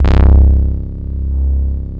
TAURUS  C2.wav